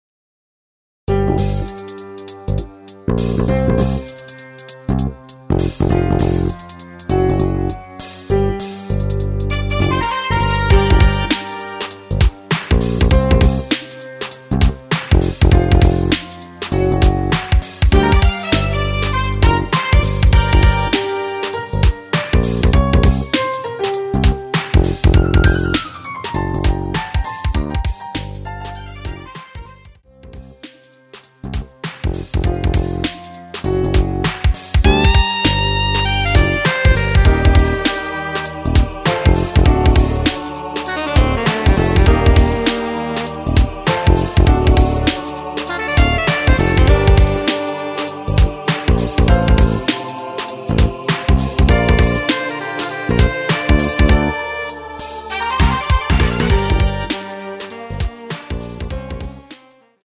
원키에서(-2)내린 MR입니다.
엔딩이 페이드 아웃에 너무길어 4마디로 편곡하여 엔딩을 만들었습니다.
Gm
앞부분30초, 뒷부분30초씩 편집해서 올려 드리고 있습니다.